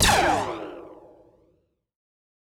fire_laser3.wav